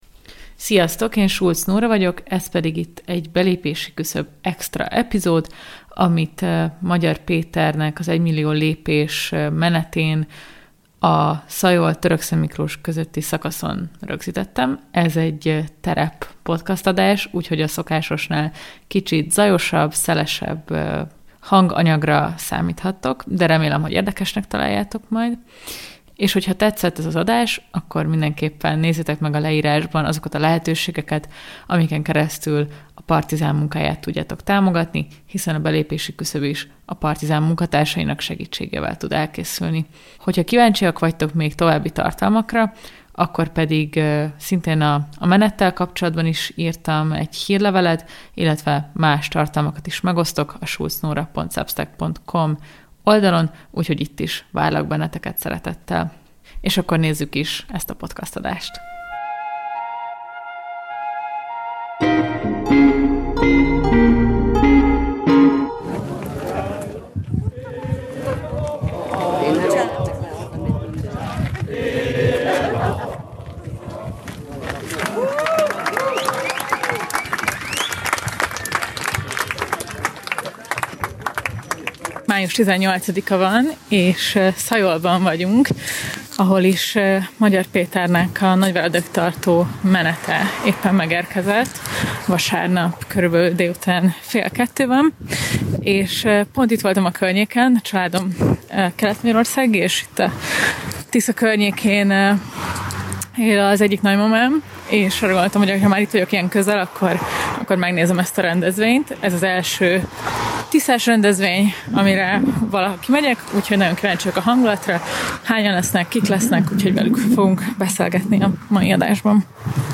Az adást a Partizán Önkéntesközpontjában vettük fel.